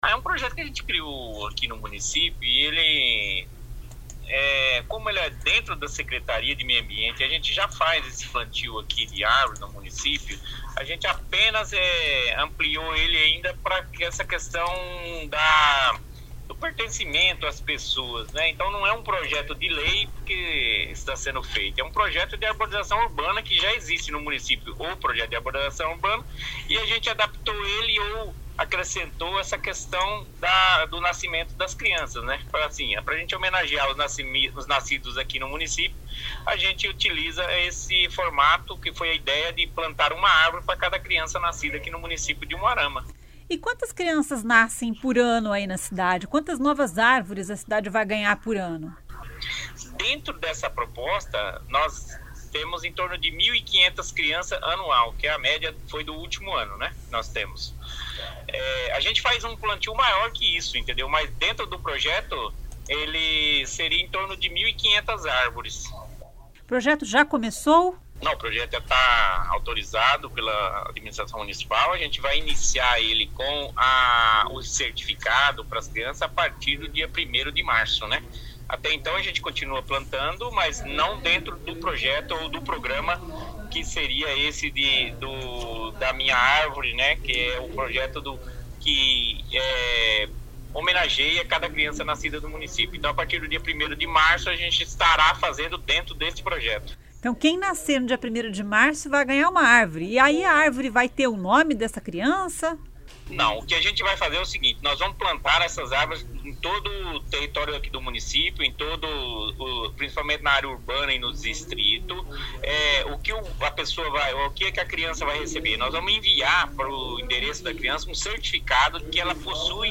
O projeto “Minha árvore” é da Secretaria de Meio Ambiente e prevê o plantio de uma árvore para cada criança que nascer na cidade a partir do dia 1º de março. O secretário Cláudio Marconi explica.